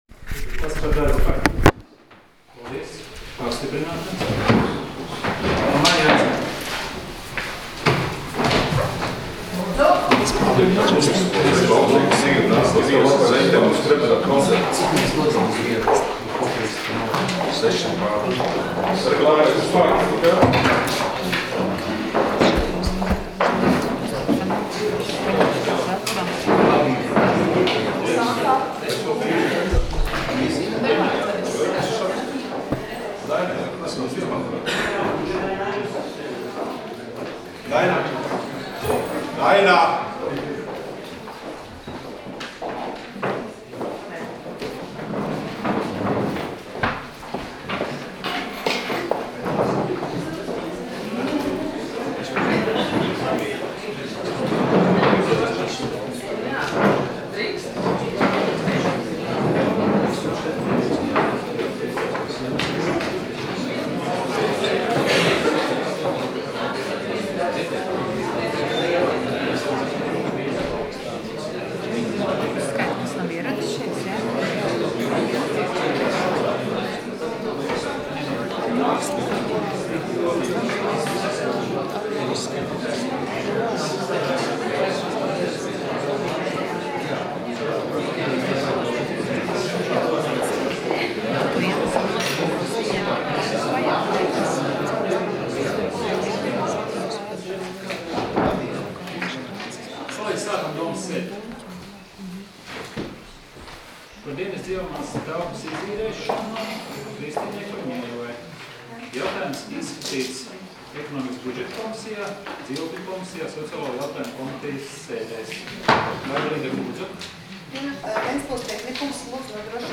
Domes sēdes 13.05.2016. audioieraksts